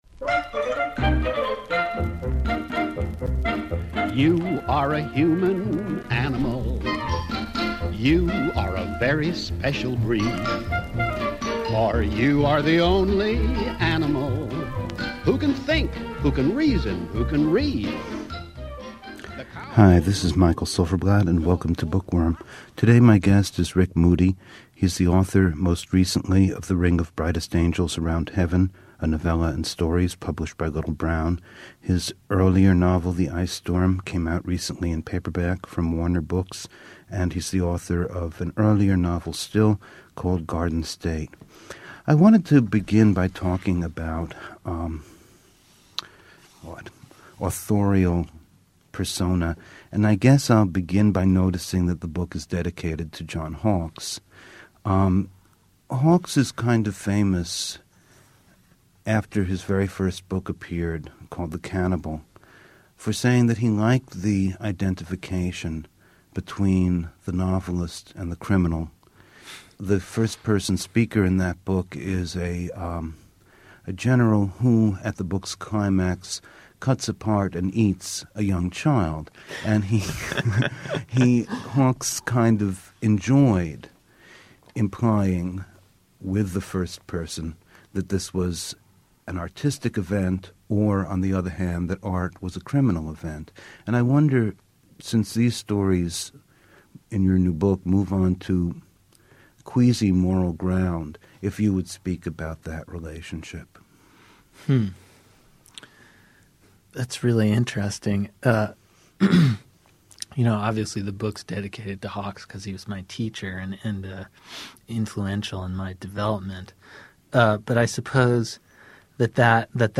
A conversation with Rick Moody about The Ring of Brightest Angels Around Heaven (Little Brown) and the literary values of Generation X.